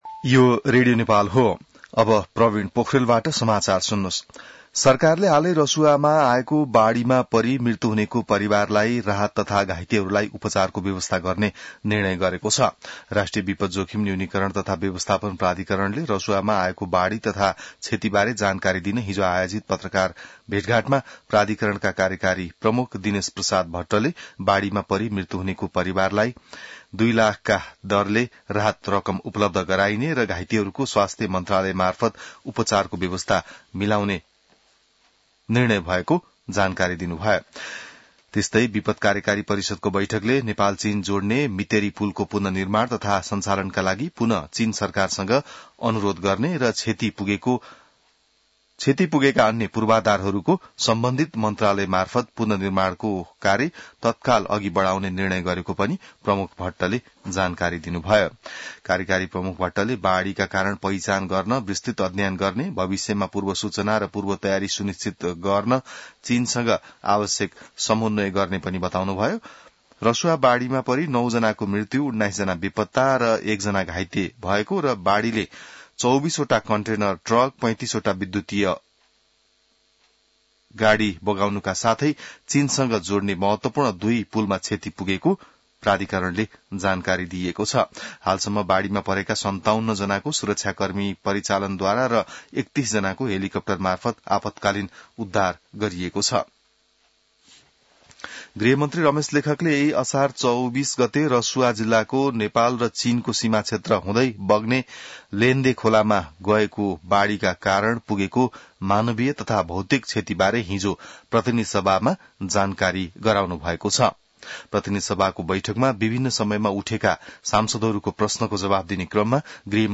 बिहान ६ बजेको नेपाली समाचार : २६ असार , २०८२